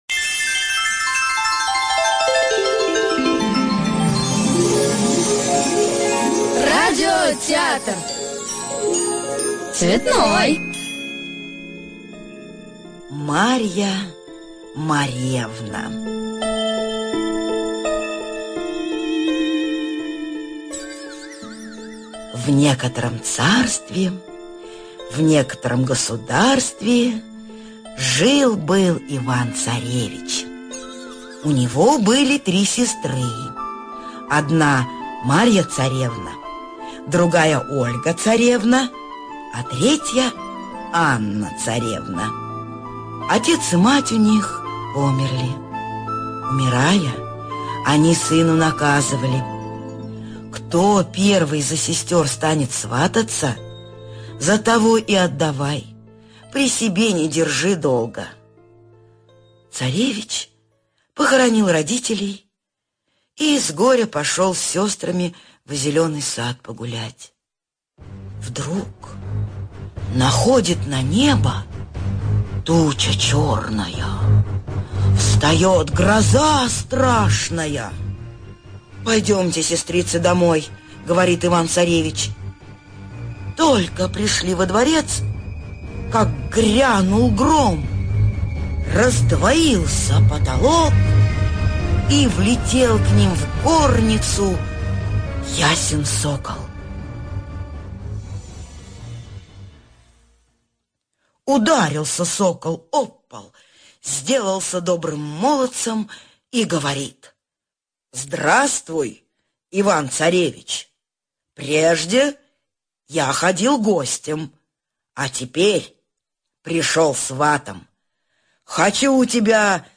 ЖанрРадиопрограммы